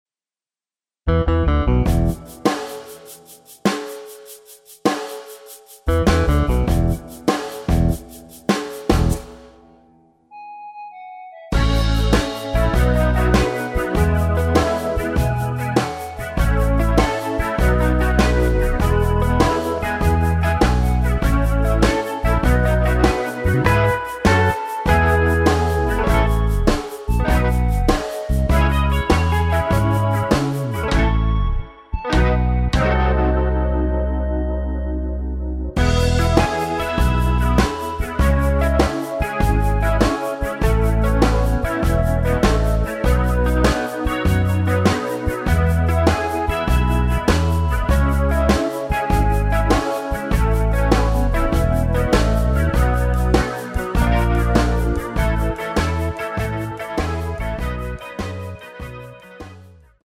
멜로디 포함된 MR 입니다.
앞부분30초, 뒷부분30초씩 편집해서 올려 드리고 있습니다.
중간에 음이 끈어지고 다시 나오는 이유는